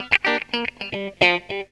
GTR 65 GM.wav